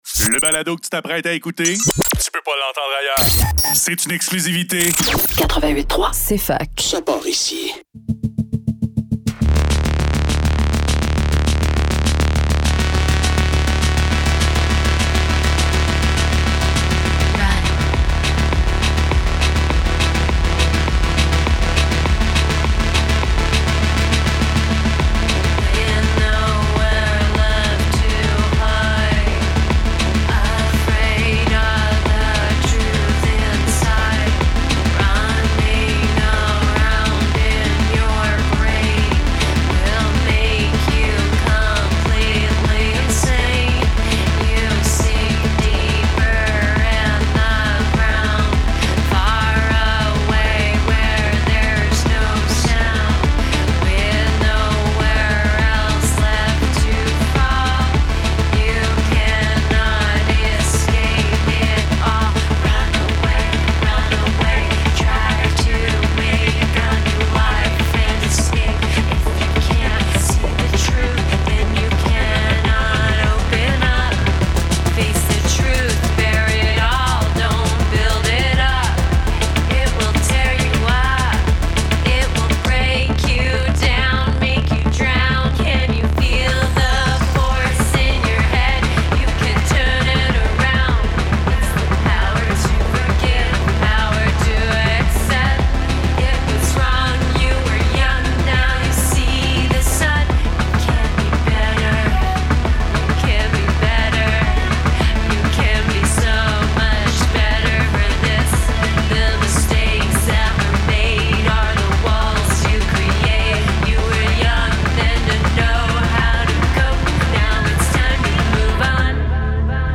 Faudrait que tout l'monde en parle - Entrevue